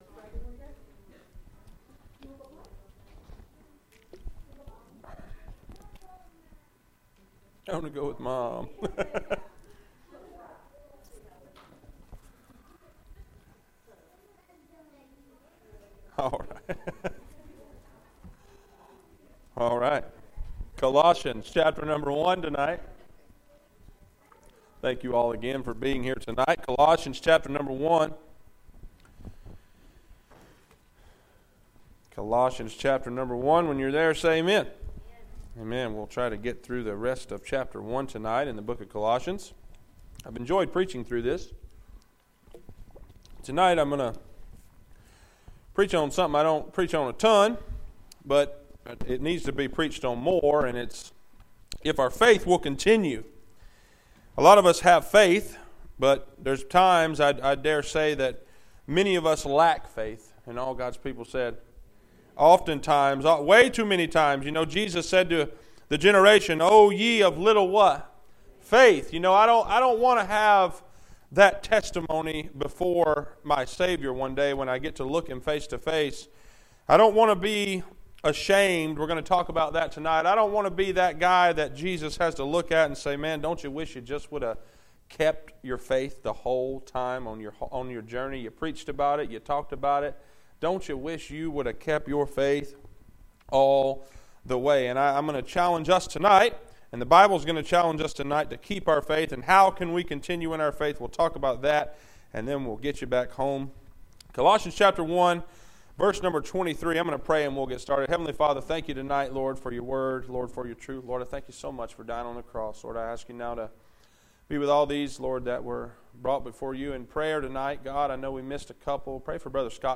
Passage: Col 1:23-29, 2 Tim 1:12-14, 3:14-17, 1:6-8, Heb 11:1-40 Service Type: Wednesday Night